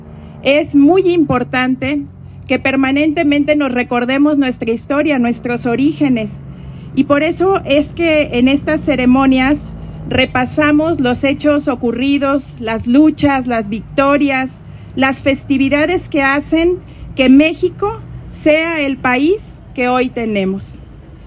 Lorena Alfaro García, Presidenta Irapuato